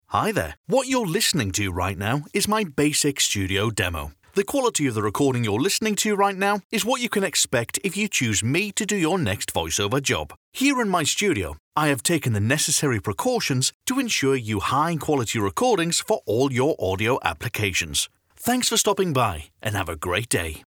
Male
British English (Native)
Approachable, Assured, Cheeky, Confident, Conversational, Corporate, Energetic, Reassuring, Sarcastic, Smooth, Warm
Geordie, RP, British, American
Microphone: Neumann TLM103 / Senheisser MKH-416 / Rode NT2